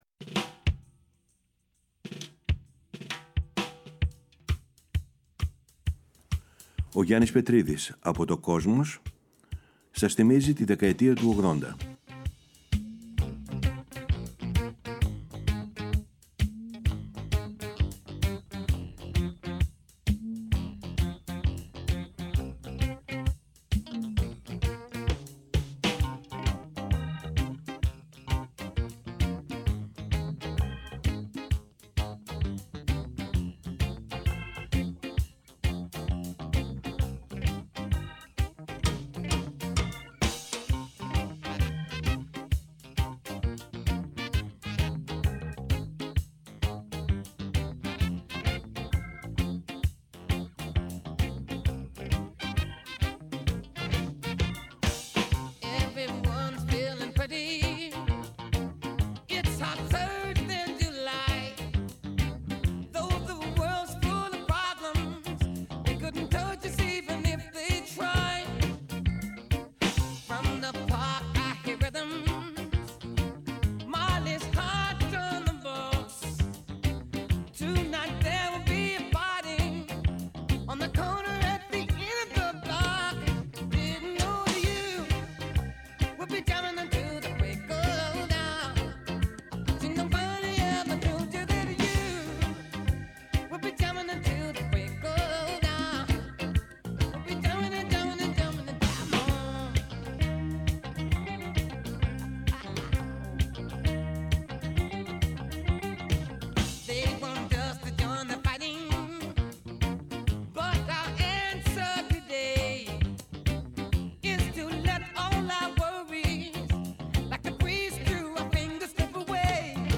Ακολουθούν μία ανασκόπηση του grunge, του τελευταίου σπουδαίου μαζικού κινήματος του ροκ, καθώς και αφιερώματα στο post punk, το trip hop, τη house, την electronica, τη χορευτική μουσική και άλλα καθοριστικά μουσικά είδη της συγκεκριμένης 20ετίας.